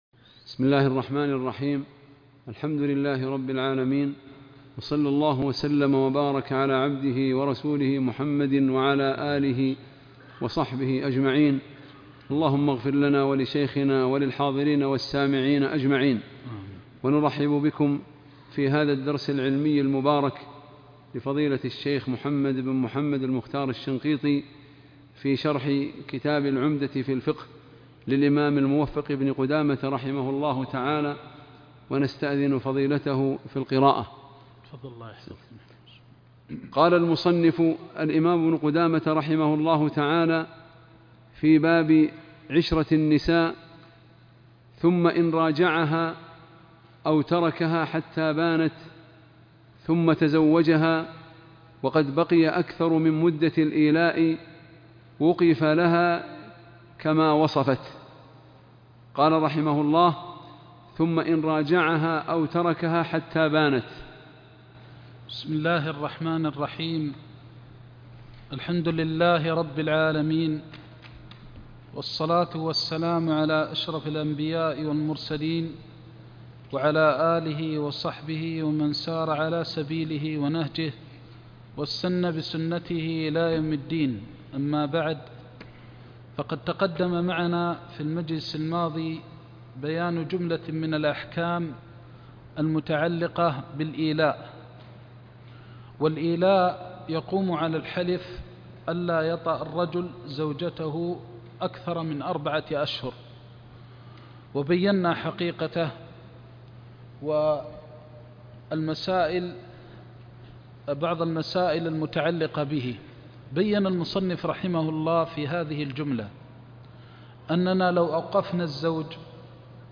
درس عمدة الفقه بالرياض تابع كتاب النكاح رقم الدرس (٧٤) - الشيخ محمد بن محمد المختار الشنقيطي